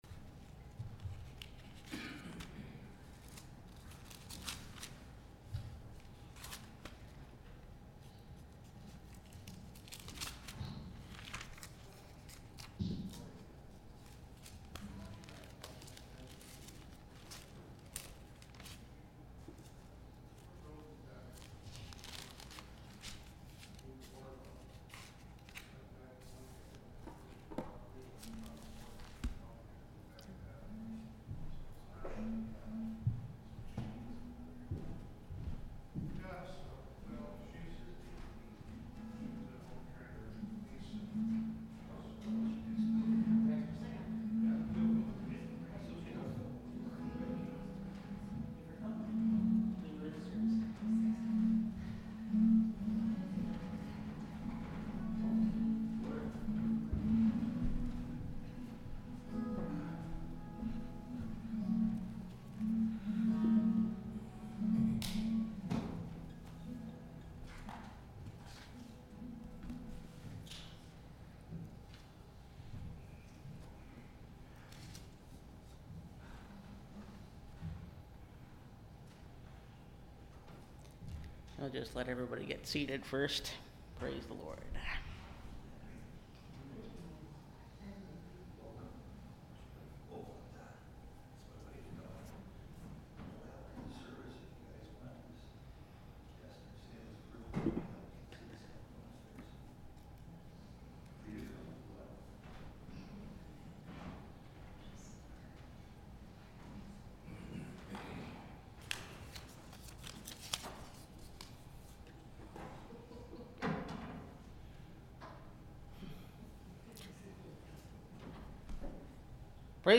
Through The Fire – Last Trumpet Ministries – Truth Tabernacle – Sermon Library